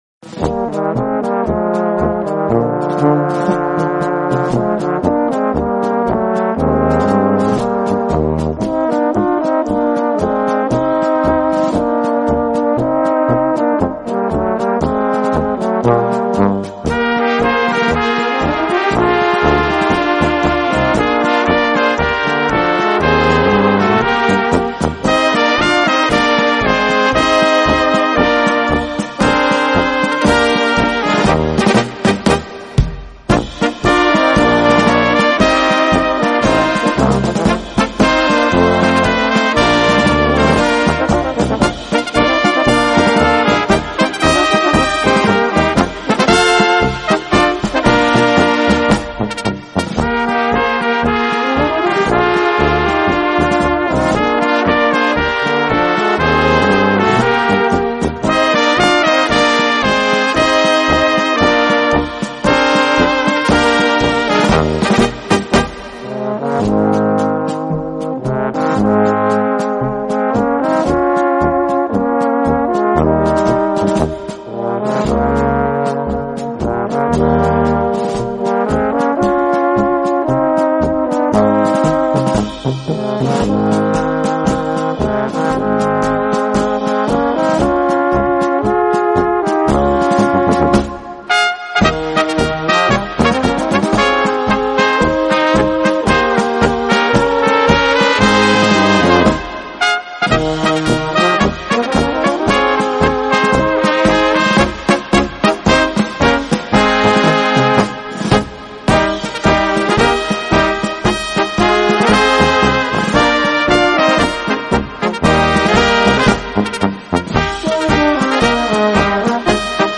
Gattung: Polka für kleine Besetzung
Besetzung: Kleine Blasmusik-Besetzung